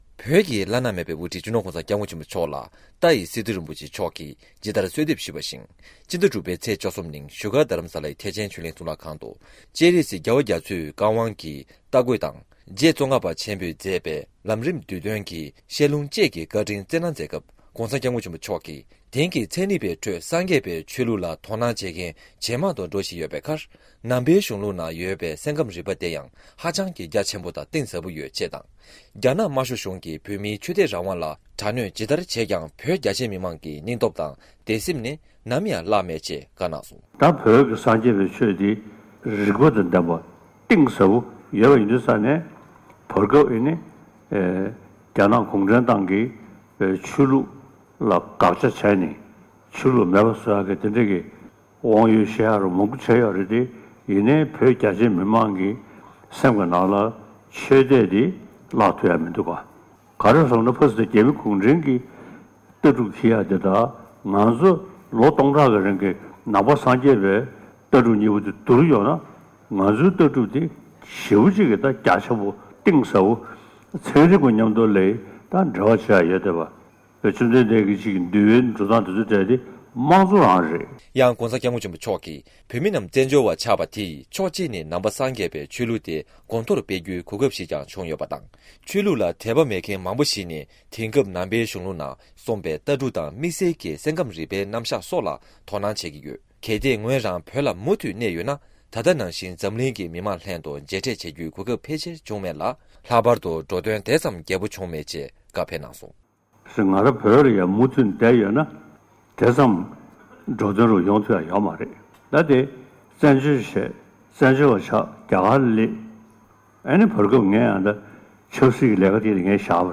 ༸གོང་ས་མཆོག་གིས། རྒྱ་གཞུང་གིས་བོད་མིའི་ཆོས་དད་རང་དབང་ཐད་དྲག་གནོན་བྱས་ཀྱང་དད་སེམས་ནི་ནམ་ཡང་བརླག་མེད་ཅེས་བཀའ་སྩལ་བ། ཕྱི་ཟླ་ ༦ པའི་ཚེས་ ༡༣ ཉིན་བཞུགས་སྒར་ཐེག་ཆེན་ཆོས་གླིང་གཙུག་ལག་ཁང་དུ།
སྒྲ་ལྡན་གསར་འགྱུར།